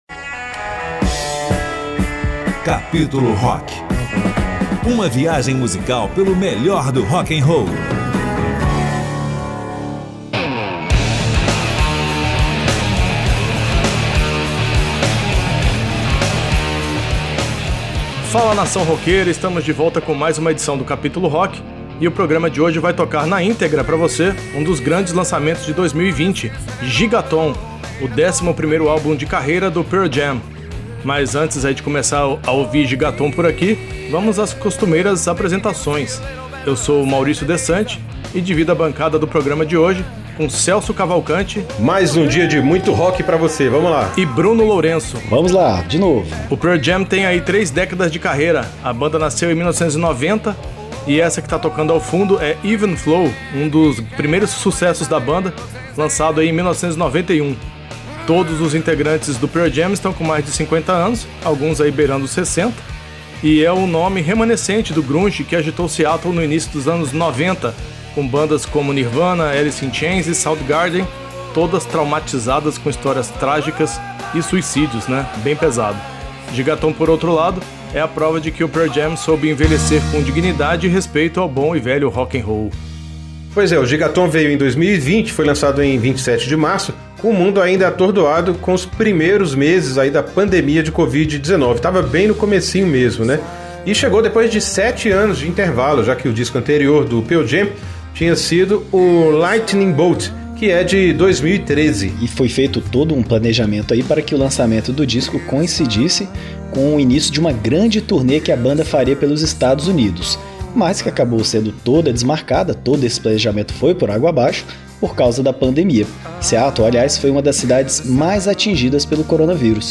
uma viagem intensa por estilos, gêneros e instrumentais